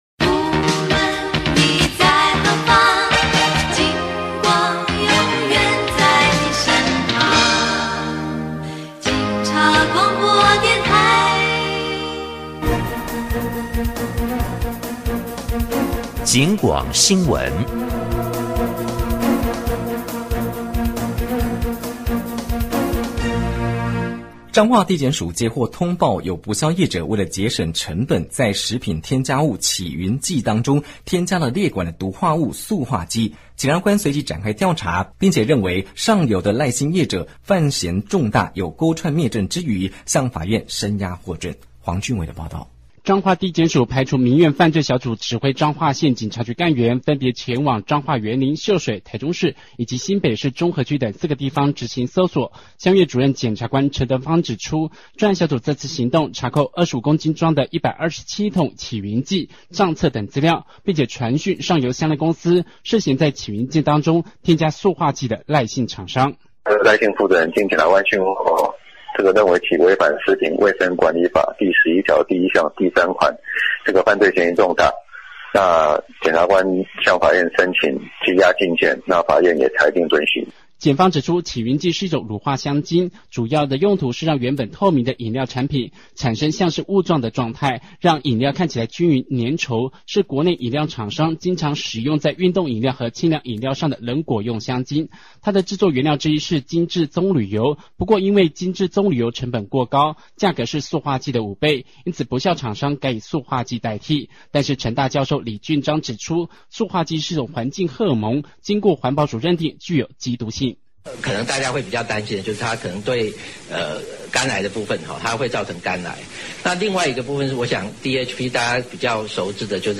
得獎理由： 對於民生關切的重大議題，有全面、完整的即時報導。製作有條理，且能收集各相關人士的發言原音，增加現場感。主持人和記者的表達都很清晰到位，表現出專業電台在重大事件發生時，應有的專業製作水準。
第十屆卓新獎廣播類即時新聞獎_警廣晚間新聞－塑化劑風暴.mp3